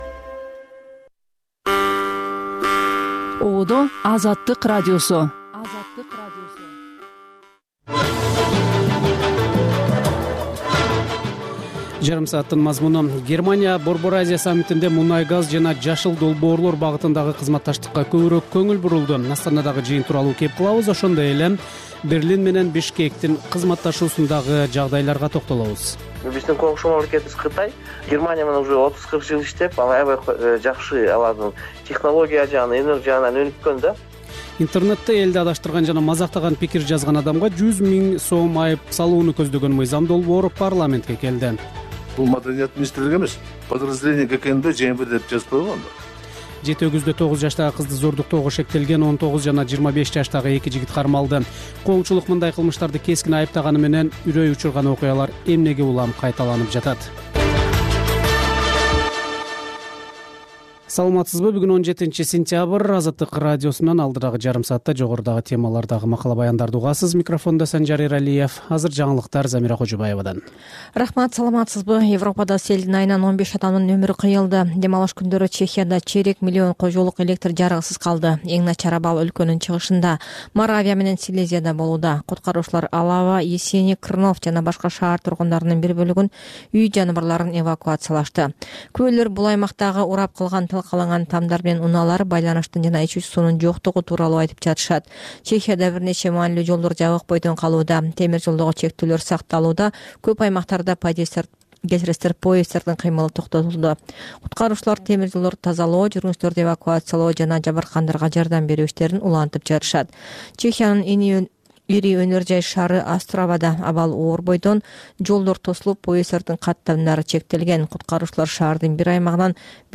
Бул үналгы берүү ар күнү Бишкек убакыты боюнча саат 19:00дөн 20:00гө чейин обого түз чыгат.